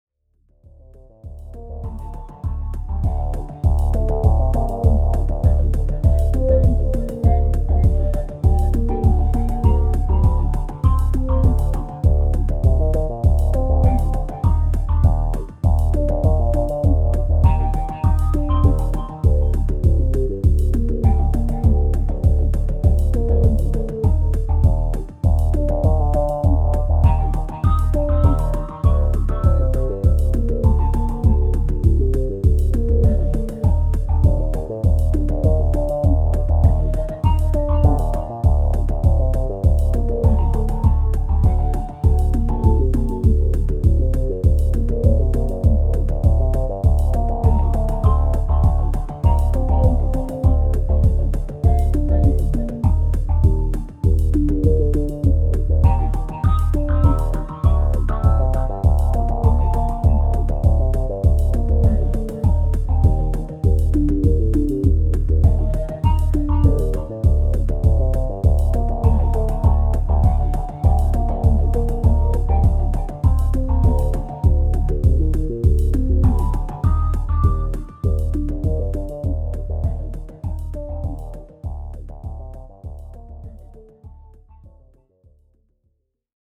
Dans la pratique j'en utilise actuellement 5 pour, les percussions, la basse et 3 instruments pouvant être rythmiques ou mélodiques.
Voici 3 ambiances musicales pour illustrer les capacités de la Société Henon.